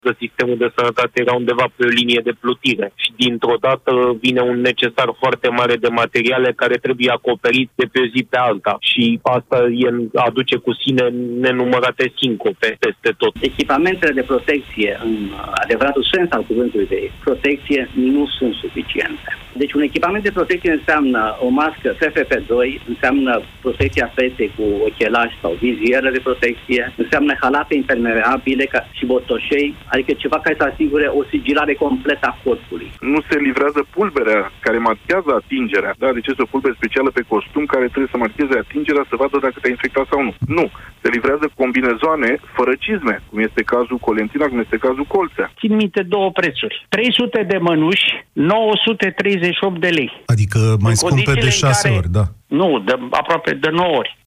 Iată câteva mărturii făcute de mai mulți medici în emisiunea România în Direct:
19mar-18-VOX-medici-despre-echipamente.mp3